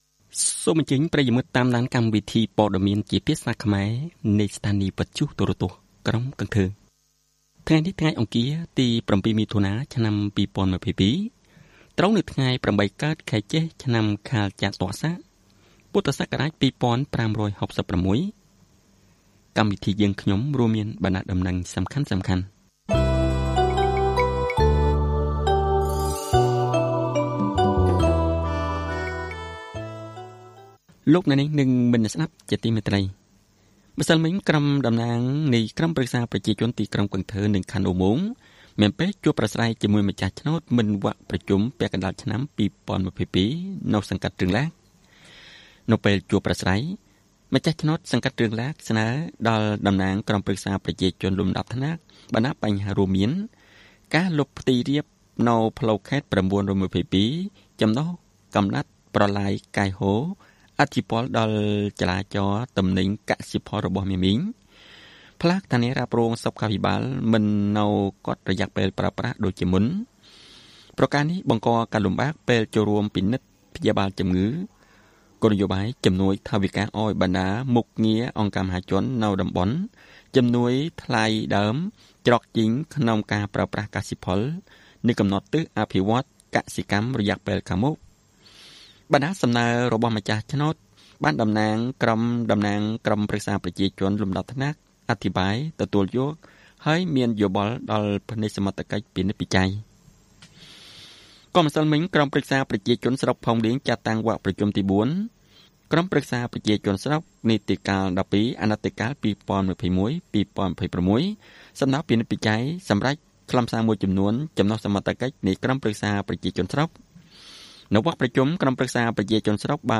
Bản tin tiếng Khmer sáng 7/6/2022
Kính mời quý thính giả nghe Bản tin tiếng Khmer sáng 7/6/2022 của Đài Phát thanh và Truyền hình thành phố Cần Thơ